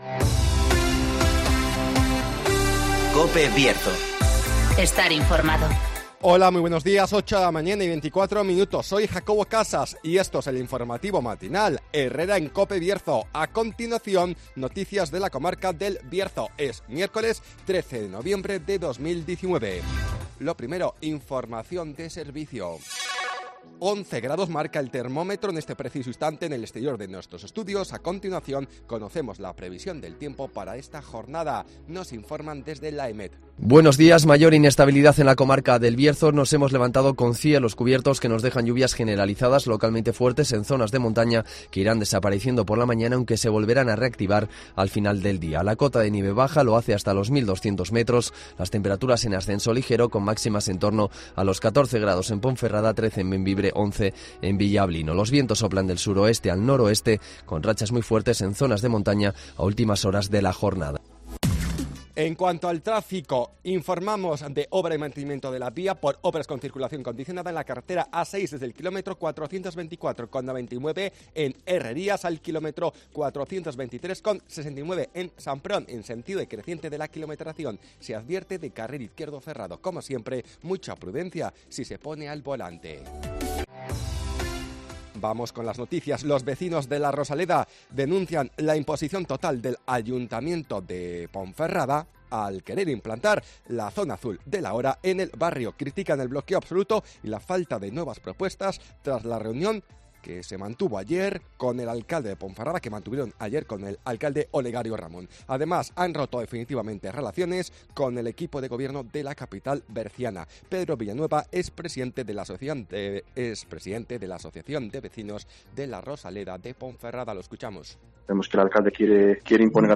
INFORMATIVOS
-Conocemos las noticias de las últimas horas de nuestra comarca, con las voces de los protagonistas